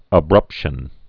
(ə-brŭpshən)